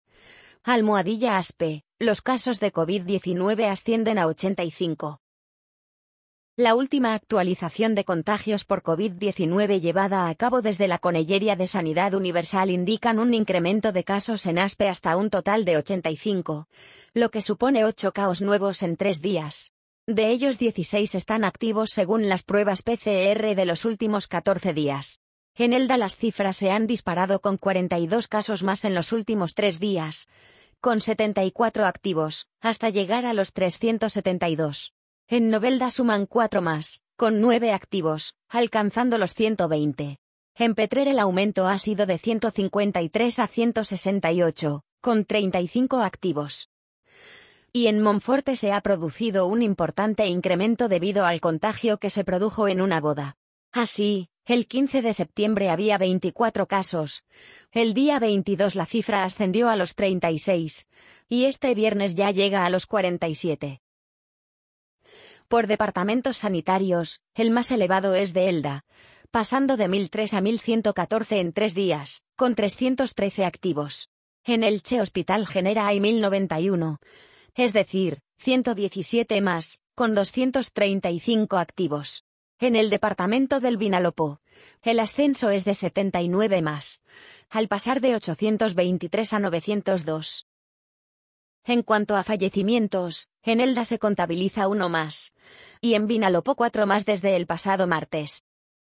amazon_polly_45271.mp3